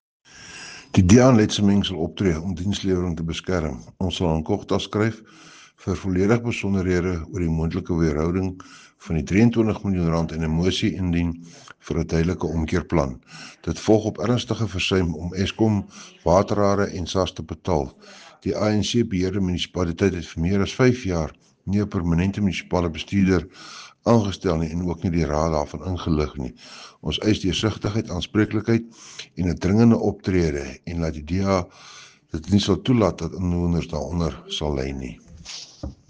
Afrikaans soundbite by Cllr Johann Steenkamp.